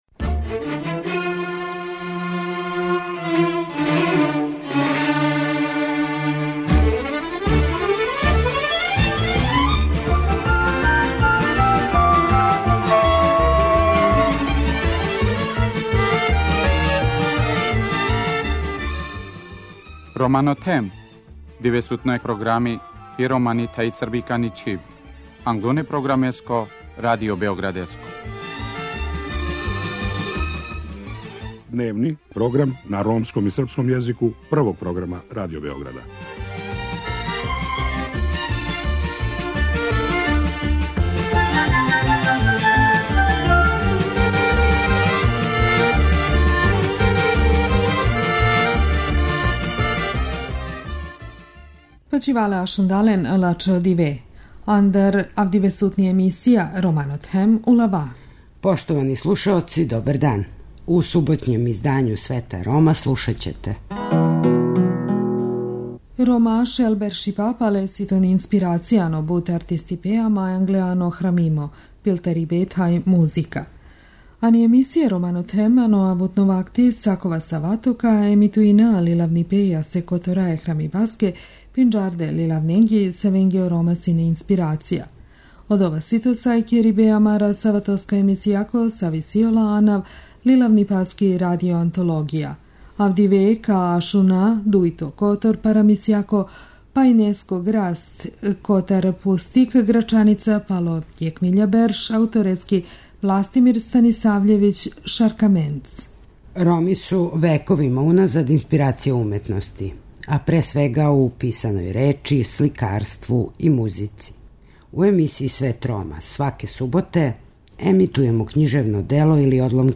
Данас слушамо други део приче "Водени коњ" из књиге Грачаница после 1000 година аутора Властимира Станисављевића -Шаркаменца.